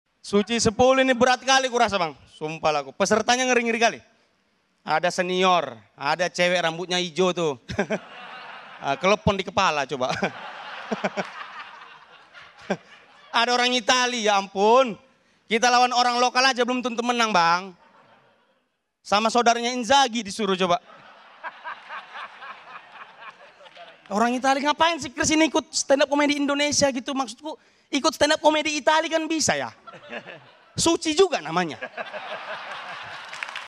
Stand Up Comedy
You Just Search Sound Effects And Download. tiktok funny sound hahaha Download Sound Effect Home